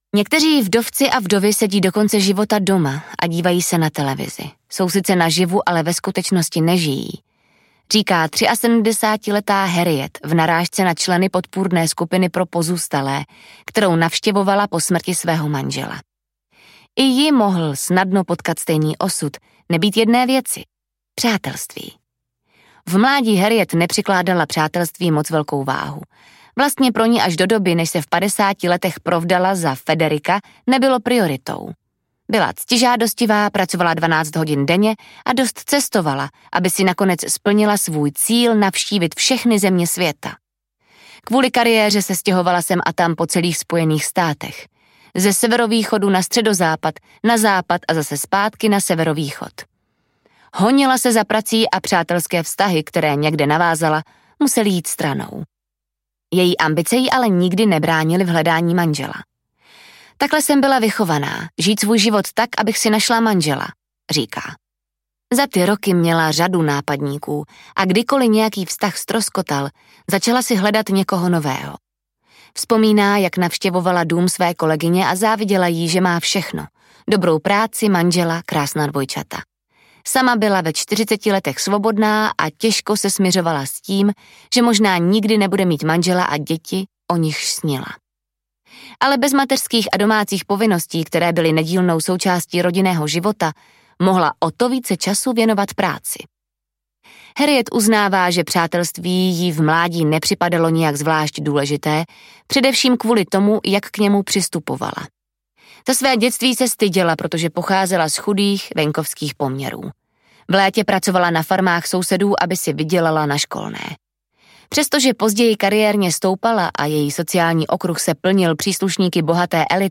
Pouto přátelství audiokniha
Ukázka z knihy